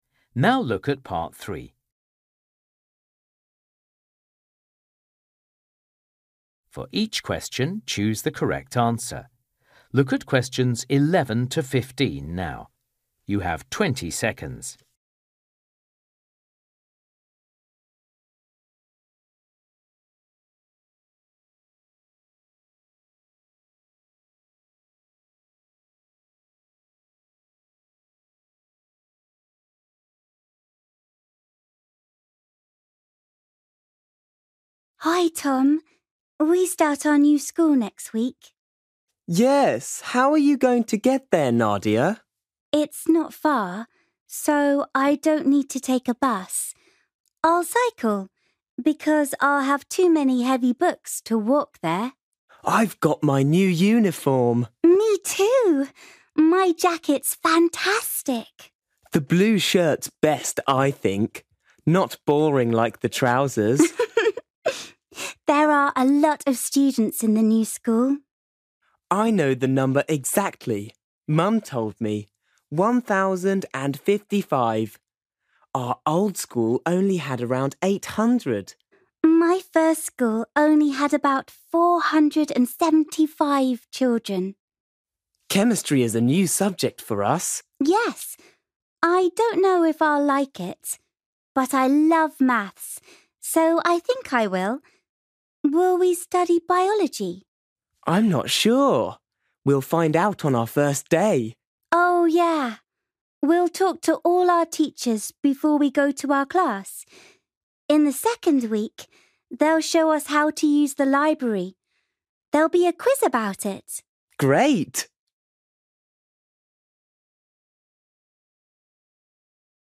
You will hear Nadia and Tom talking about their new school.